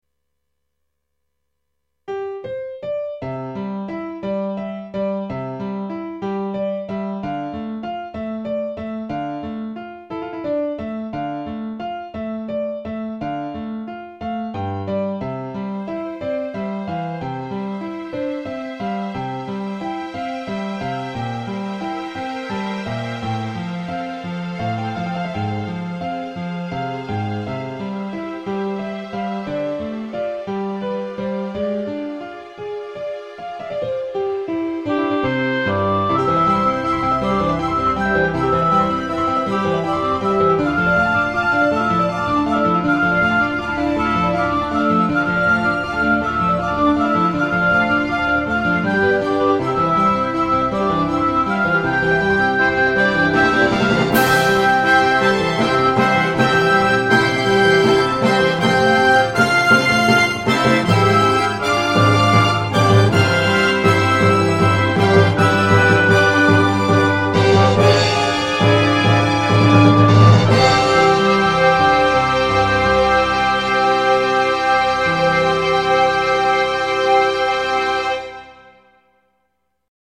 Novembre 2000 (breve invenzione orchestrale)